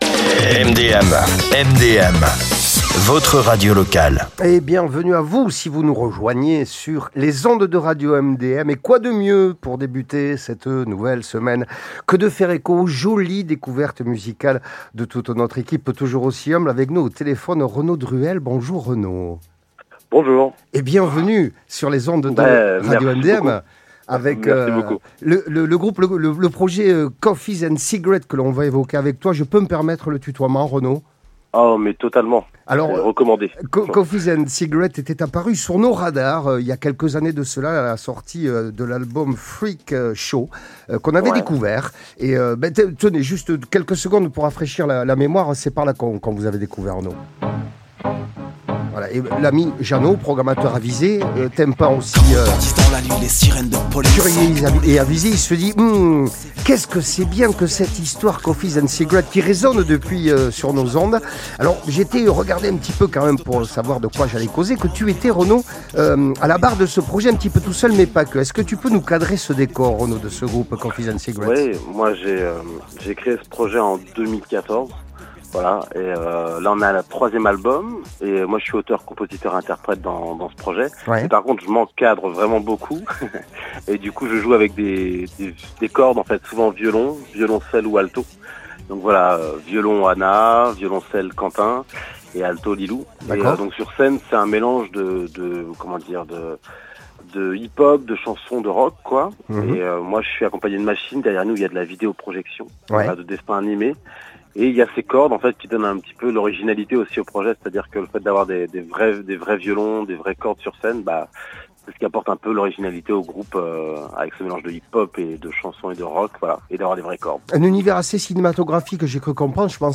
Toujours aussi envoûtant et inspiré, Rencontre…